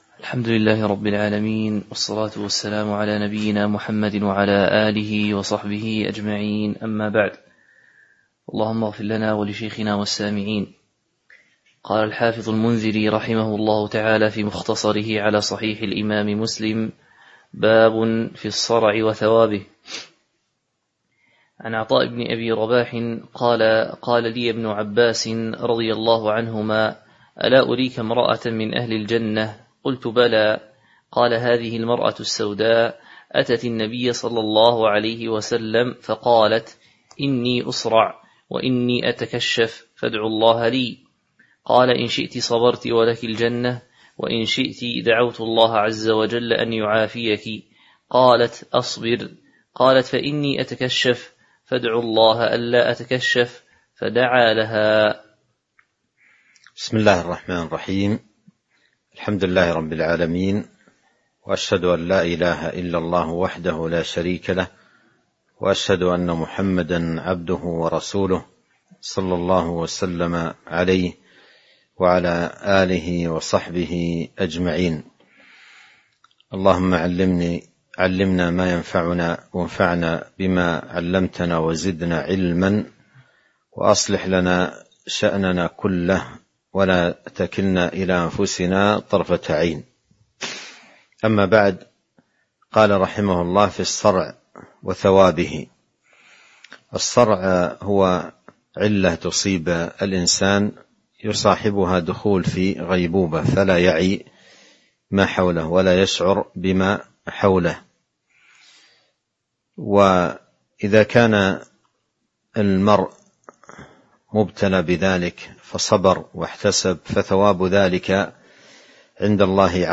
تاريخ النشر ٢٢ رجب ١٤٤٣ هـ المكان: المسجد النبوي الشيخ: فضيلة الشيخ عبد الرزاق بن عبد المحسن البدر فضيلة الشيخ عبد الرزاق بن عبد المحسن البدر باب في الصرع وثوابه (02)